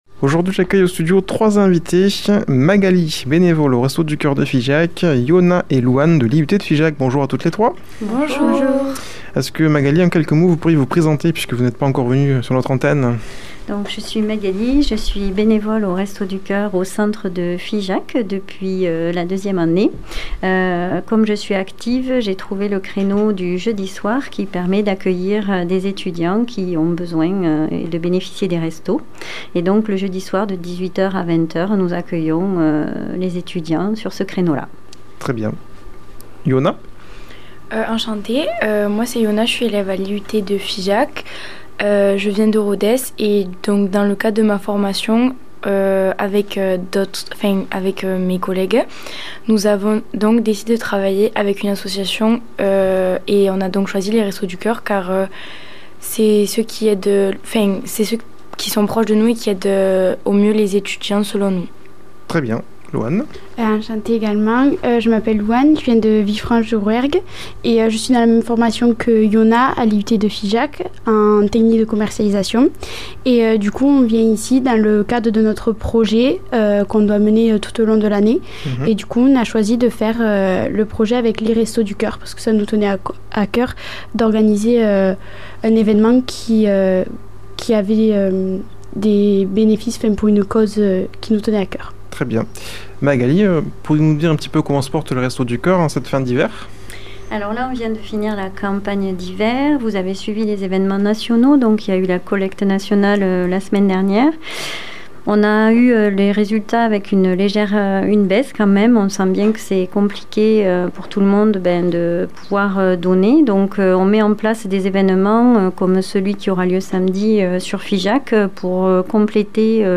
a comme invitées au studio
Présentateur